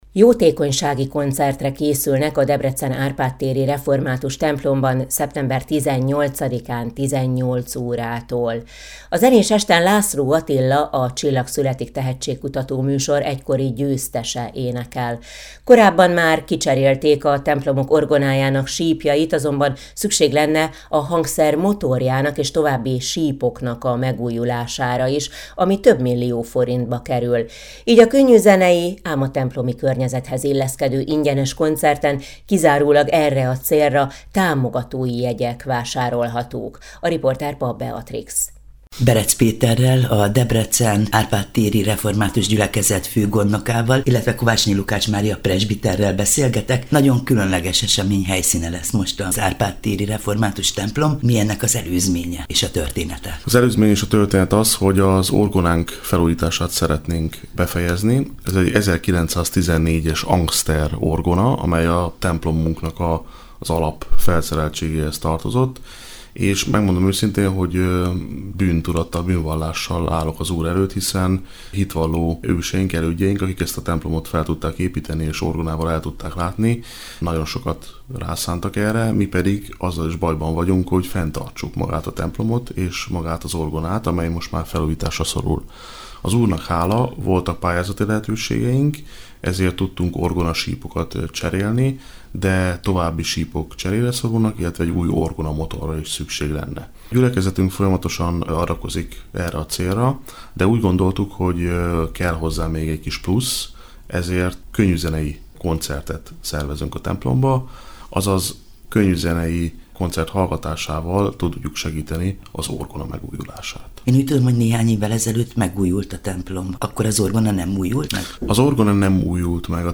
koncert
16-40-arpad_ter_jotekonys_konc.mp3